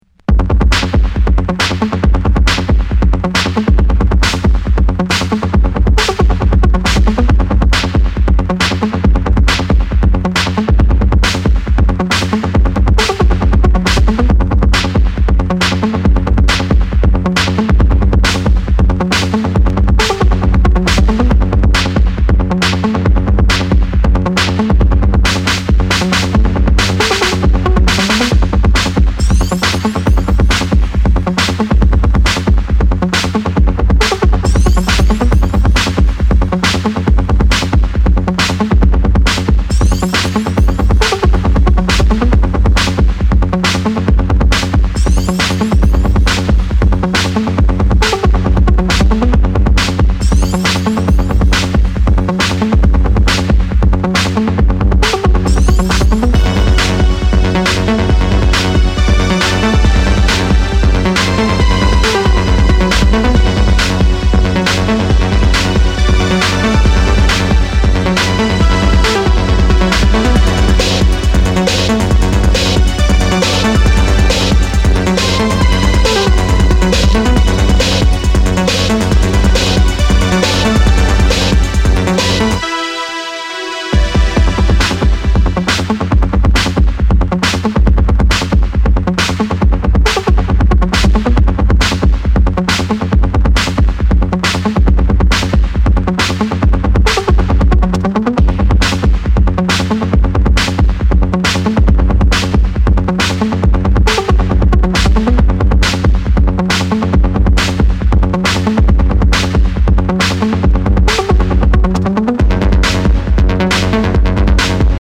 尖ったインダストリアル・ビート主体ながらエレクトロ、テクノ、ミニマルをもACID感覚も絡めつつ突き進む狂気の全13曲！